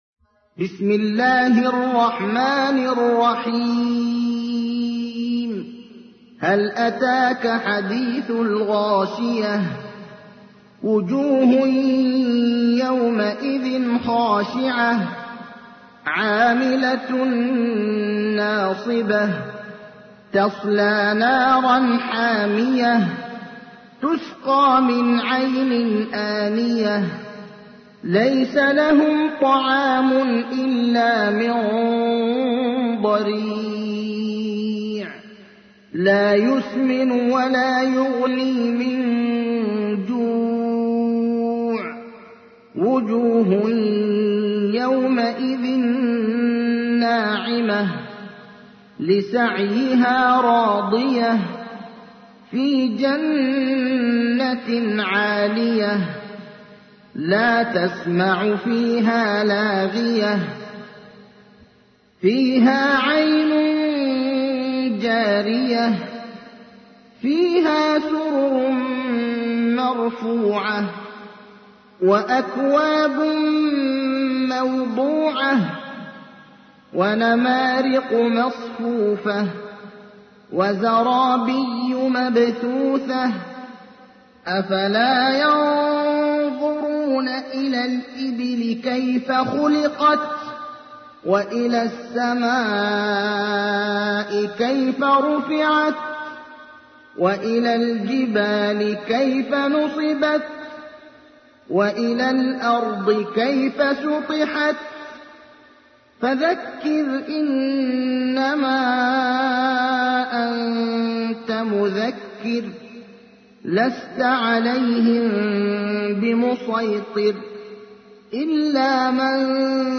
تحميل : 88. سورة الغاشية / القارئ ابراهيم الأخضر / القرآن الكريم / موقع يا حسين